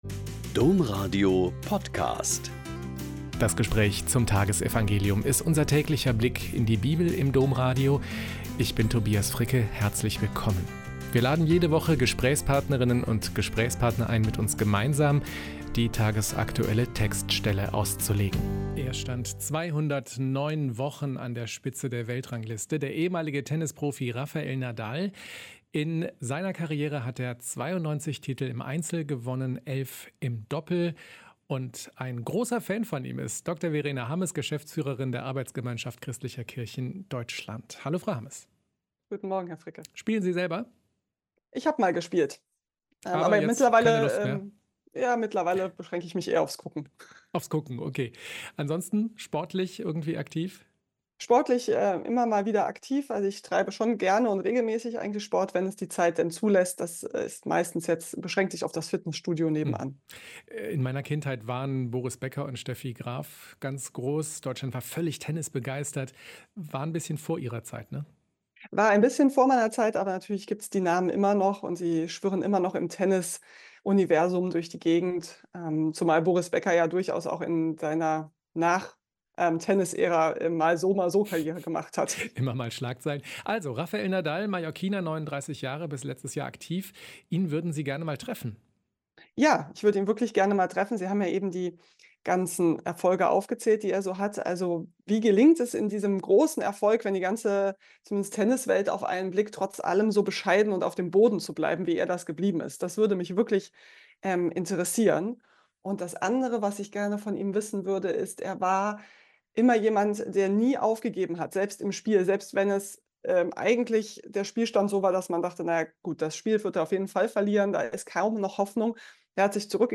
Mt 7,15-20 - Gespräch